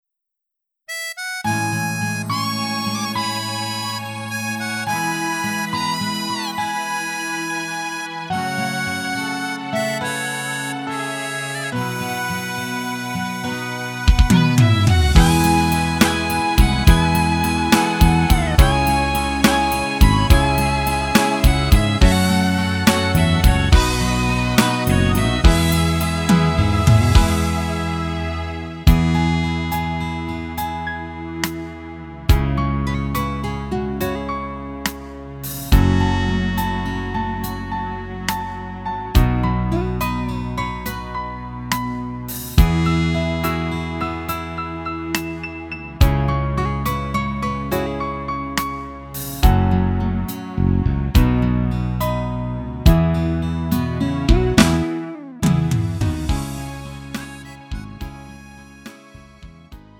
음정 원키 3:42
장르 구분 Lite MR